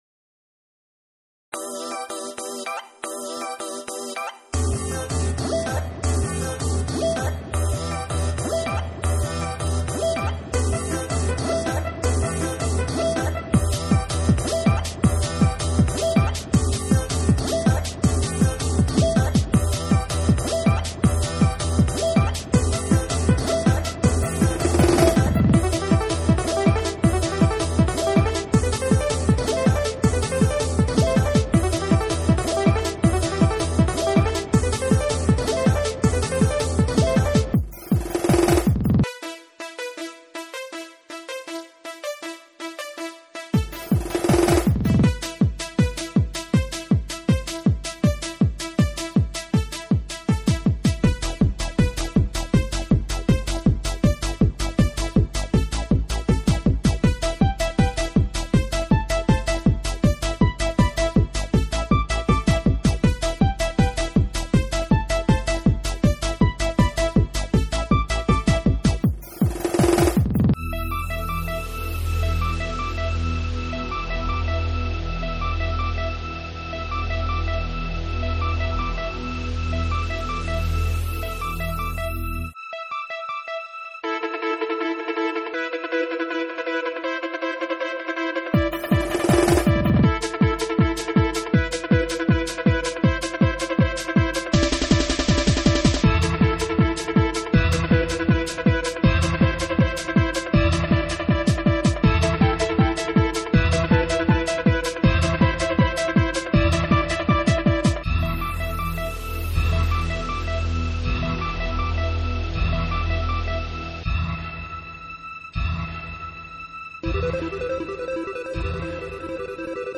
Techno
The beginning is everyone getting ready to "warp" (imagine some dramatic scene from a generic B+ sci-fi movie), then the countdown, then the euphoria of being in another dimension, then the tumultuous ride home.